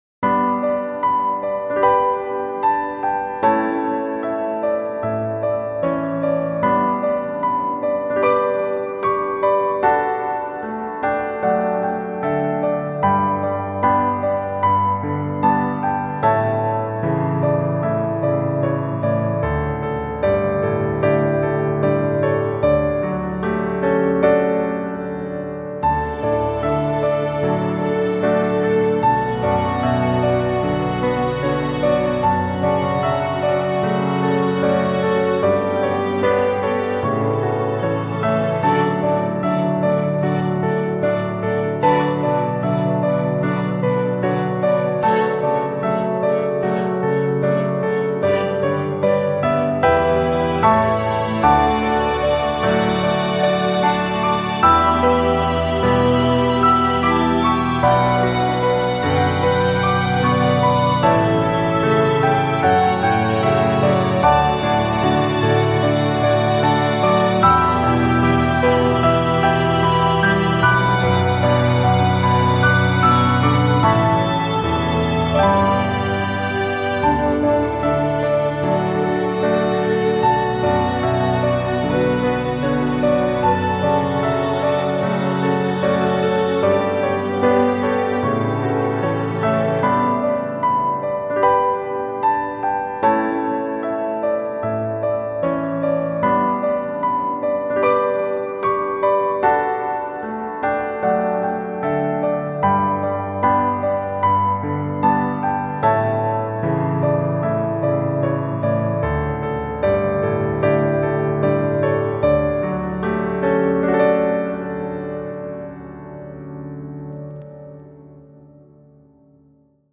優しい雰囲気を持ったピアノメインのBGMです。
BGM ピアノ ストリングス バラード ヒーリング